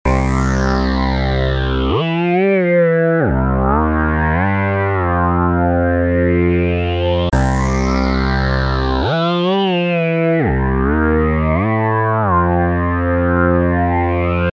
Descarga de Sonidos mp3 Gratis: sintetizador 9.